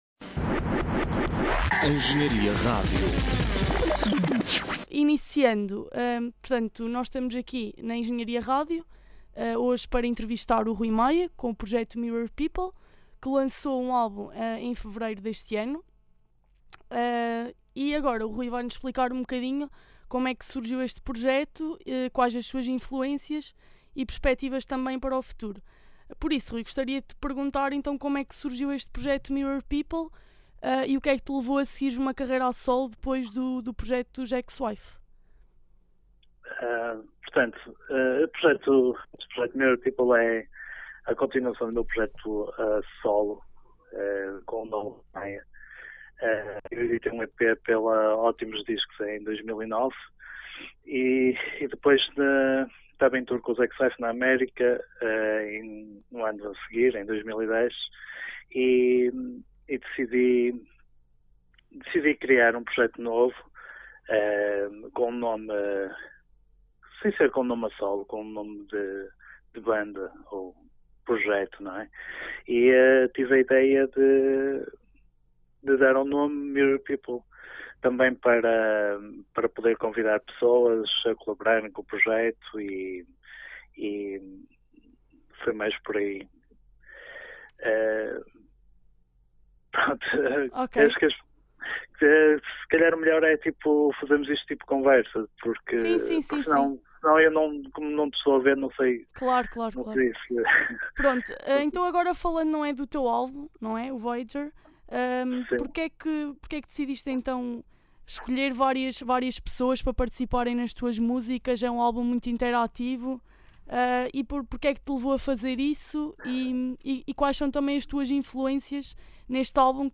Entrevista Mirror People - Engenharia Rádio